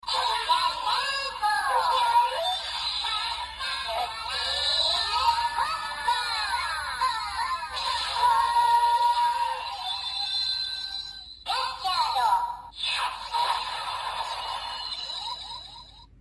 歌查德饱藏音效.MP3